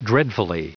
Prononciation du mot dreadfully en anglais (fichier audio)
Prononciation du mot : dreadfully